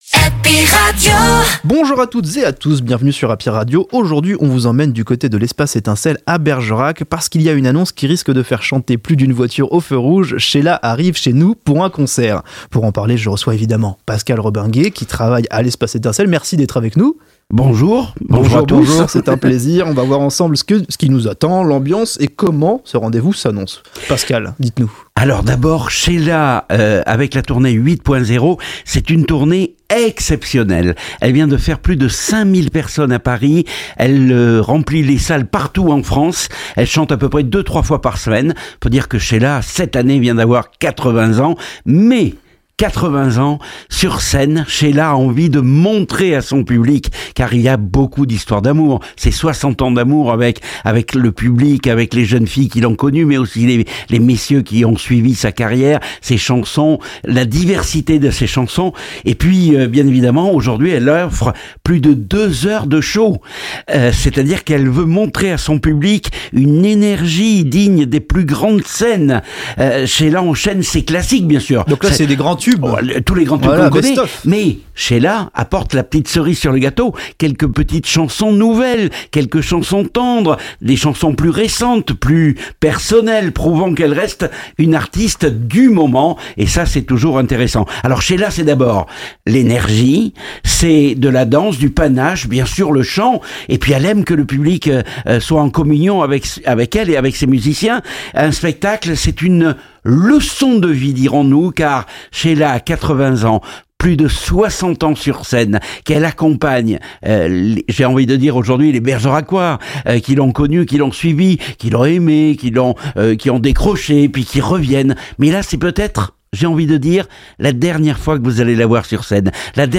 Les interviews Happy Radio Sheila à Bergerac